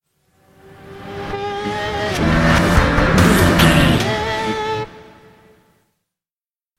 In-crescendo
Thriller
Aeolian/Minor
synthesiser
drum machine
electric guitar